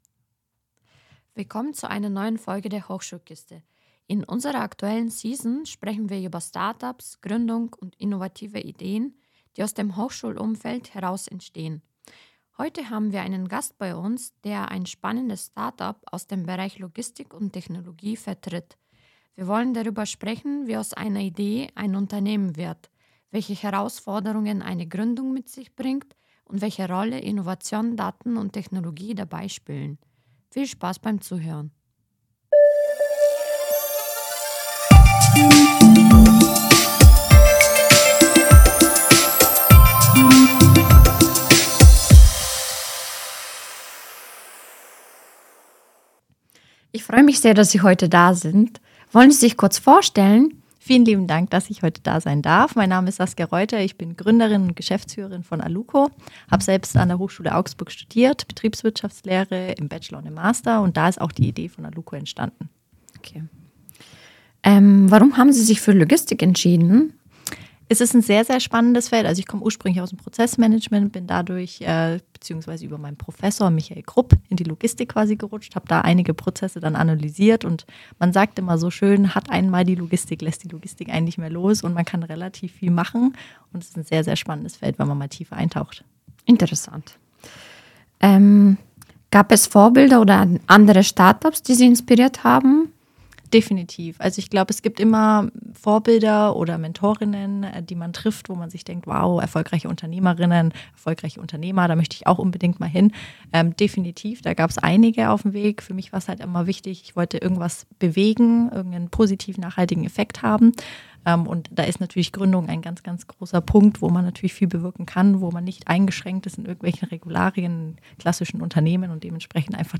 Das Interview bietet wertvolle Tipps für Studierende, die selbst überlegen, ein Unternehmen zu gründen oder in einem Startup zu arbeiten.